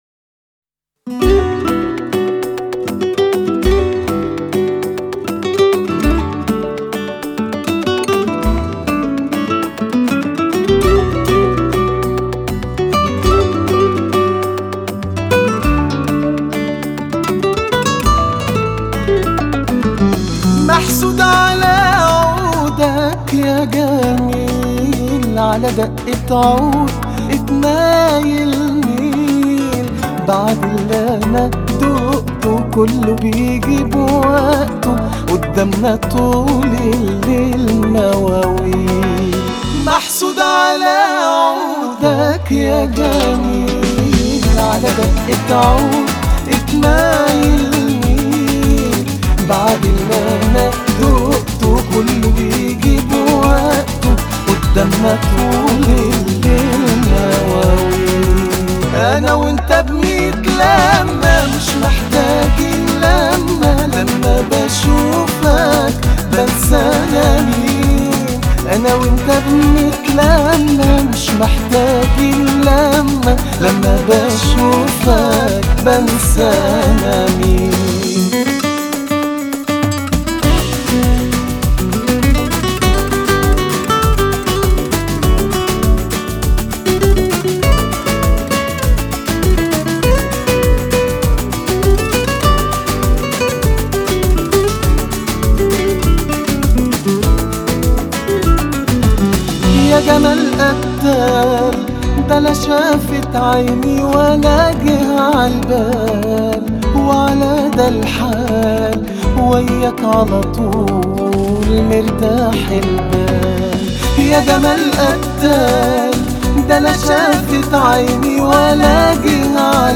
آهنگ عربی